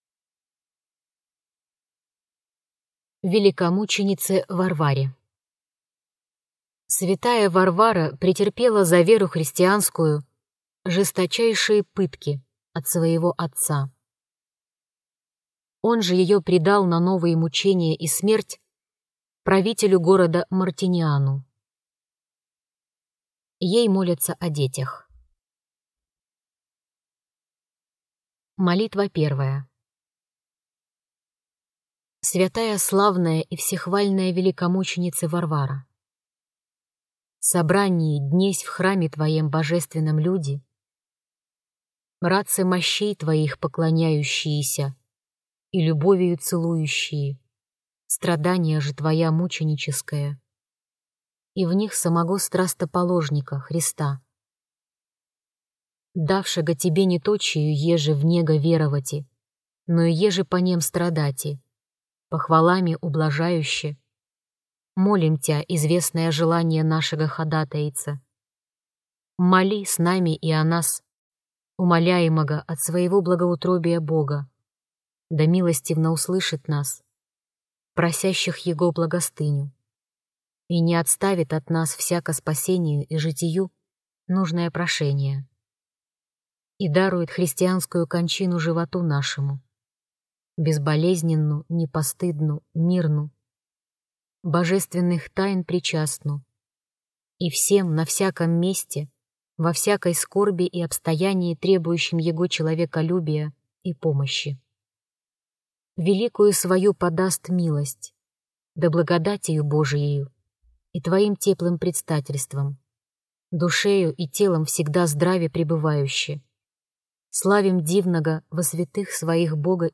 Аудиокнига Молитвы за детей | Библиотека аудиокниг